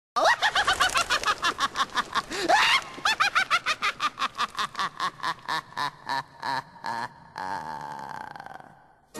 Play, download and share Cryptkeeper Laugh original sound button!!!!
cryptkeeper-laugh.mp3